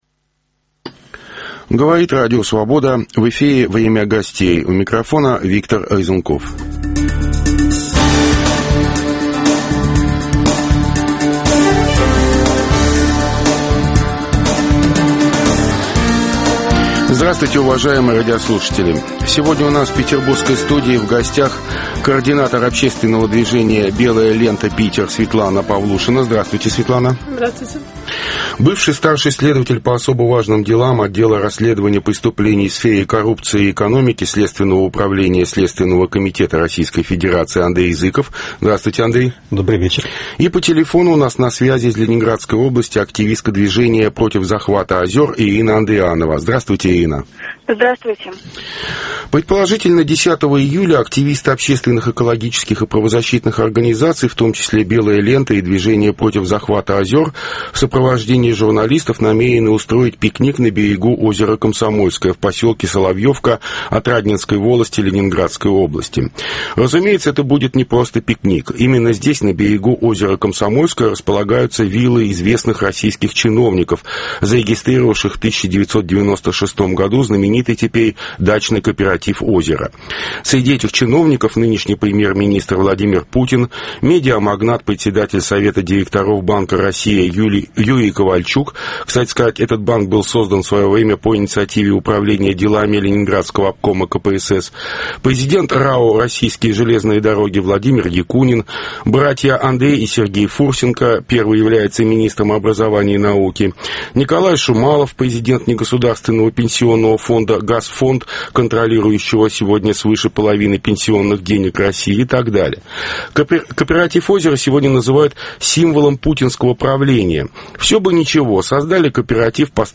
по телефону